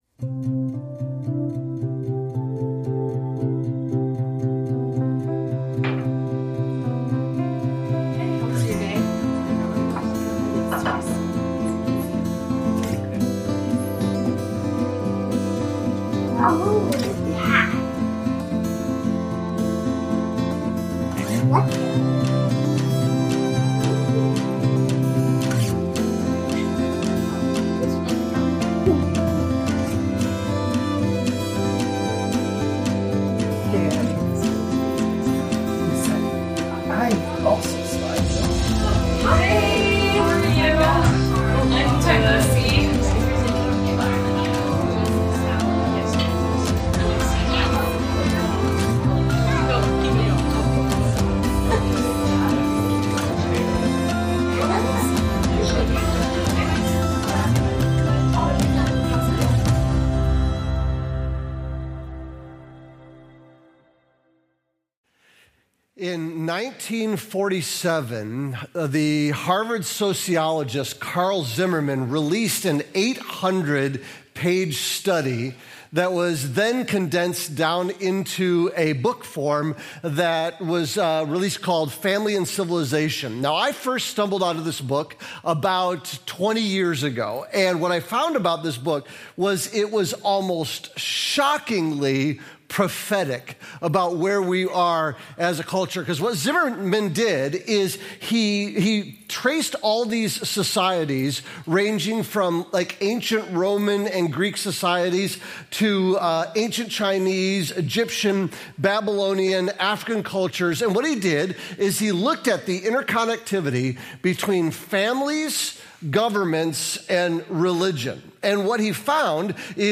A multi-site church with 3 venues located around Lansing, MI.